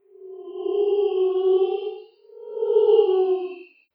whisper1.wav